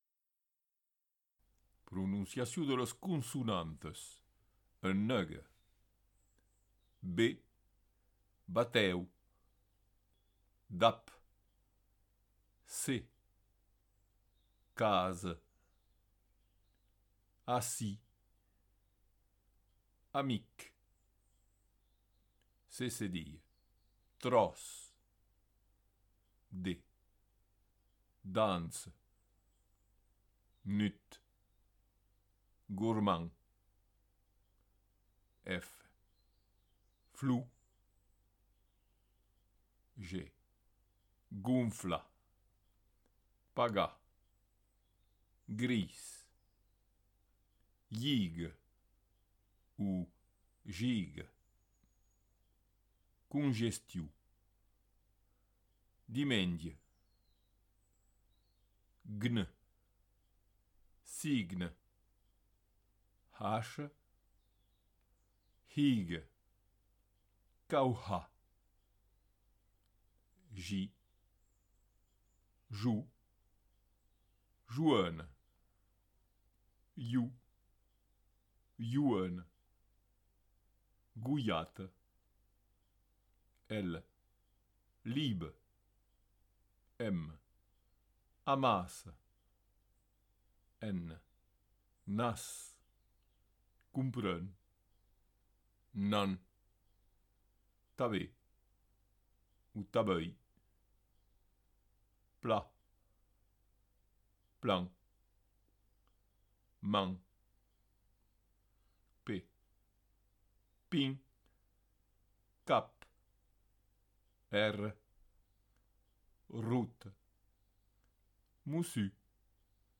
des voyelles et consonnes en gascon maritime
Fichier Son : Consonantas negas maritime